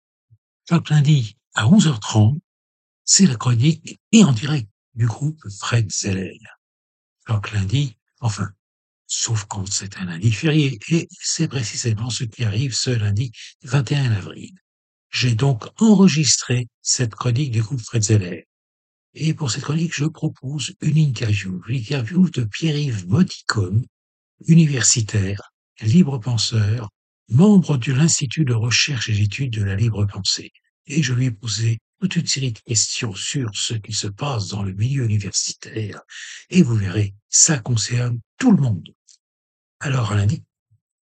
Annonce de la chronique du 21 avril